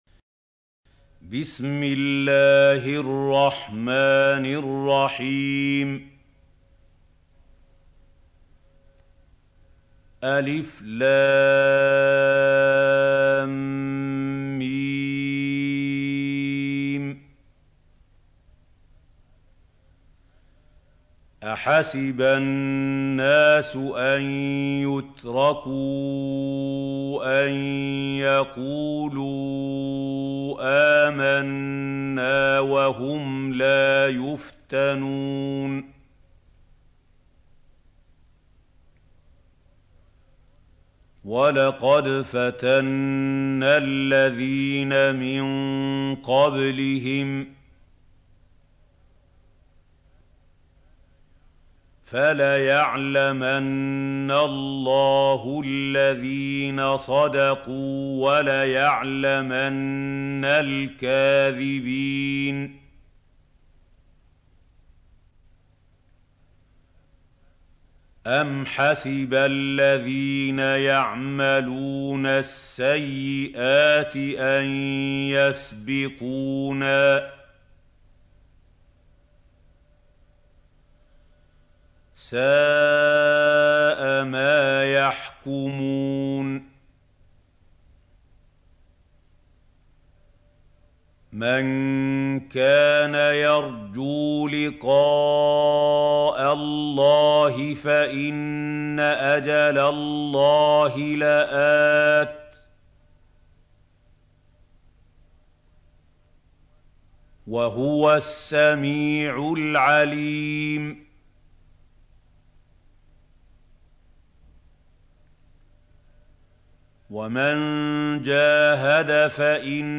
سورة العنكبوت | القارئ محمود خليل الحصري - المصحف المعلم